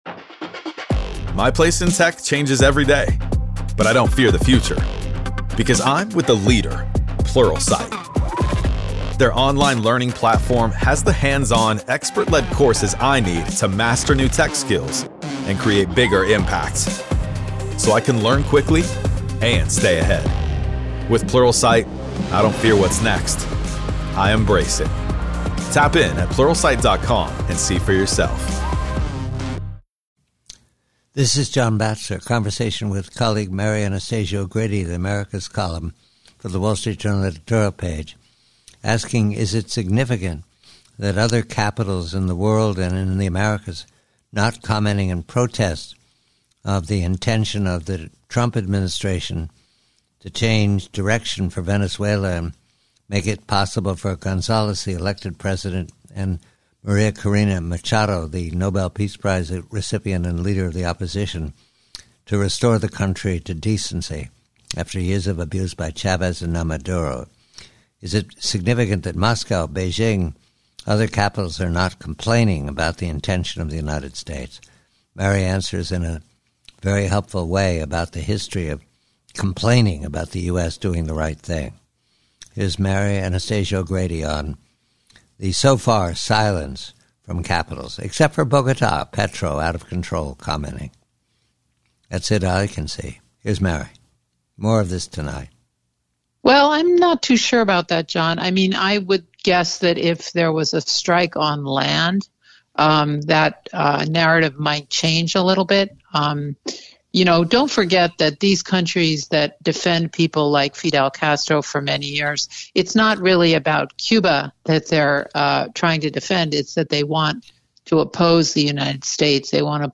GUEST NAME: Mary Anastasia O'Grady, Americas Columnist, Wall Street Journal Editorial Page